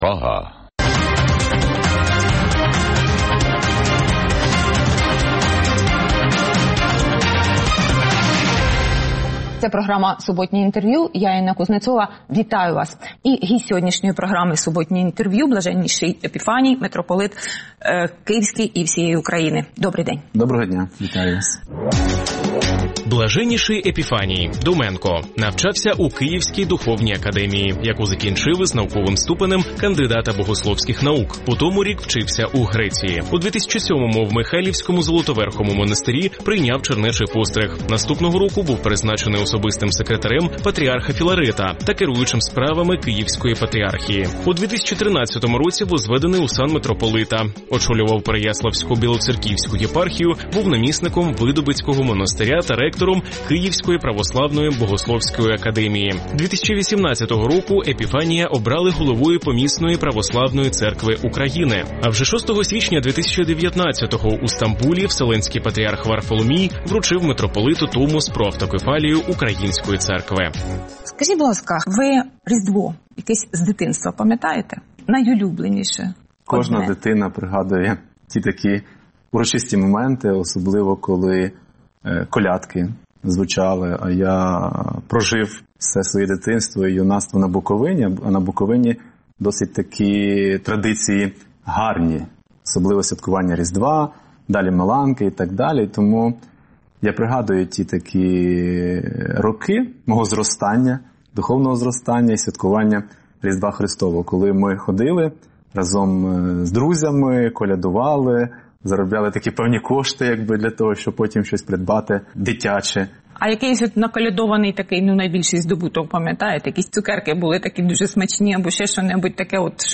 Суботнє інтерв’ю | Блаженніший Епіфаній, предстоятель Православної церкви України
Суботнє інтвер’ю - розмова про актуальні проблеми тижня. Гість відповідає, в першу чергу, на запитання друзів Радіо Свобода у Фейсбуці